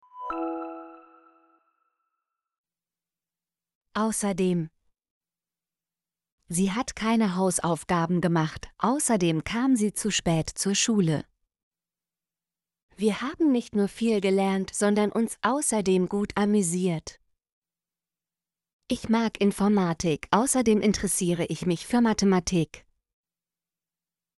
außerdem - Example Sentences & Pronunciation, German Frequency List